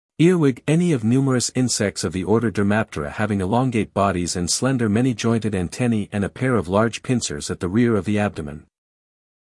英音/ ˈɪəwɪɡ / 美音/ ˈɪrwɪɡ /